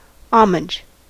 Ääntäminen
France: IPA: /ɔ.maʒ/